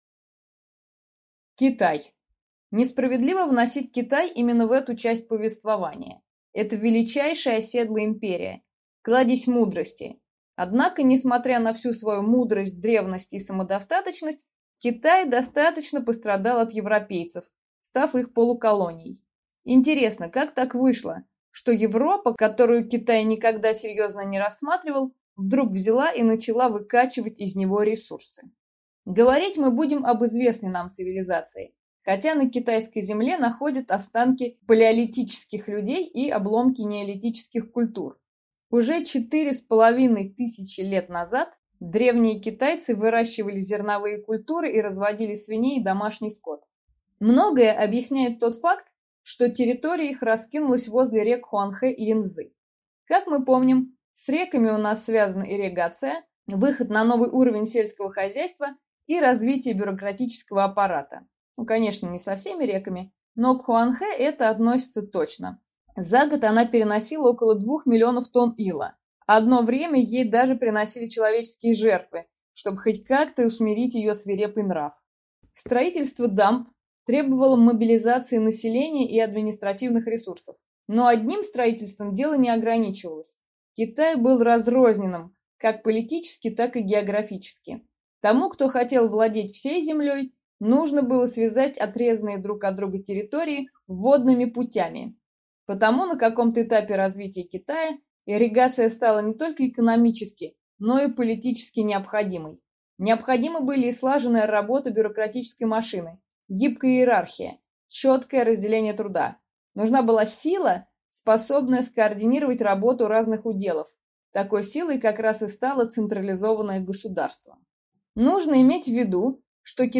Аудиокнига Китай. От Шан до Мин (18 до н.э.-17) | Библиотека аудиокниг